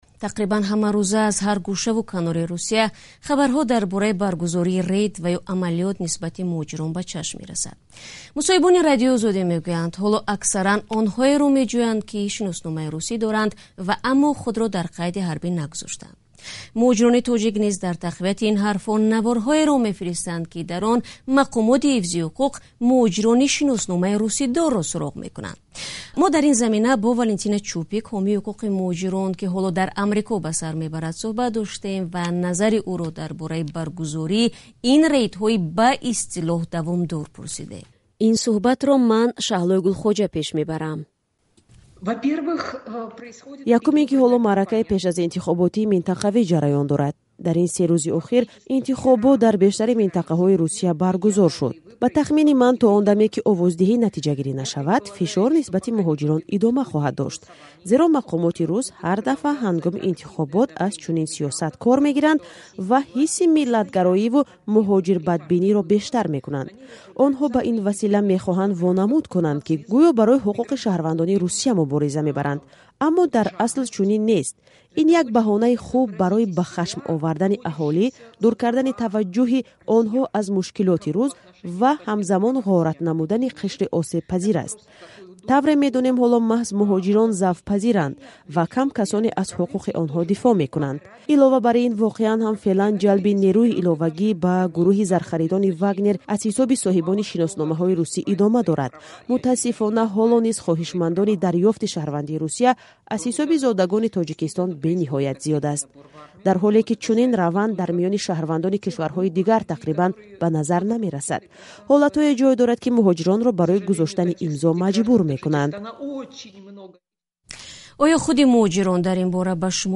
Гузоришҳои радиоӣ